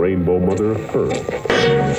120BPMRAD8-R.wav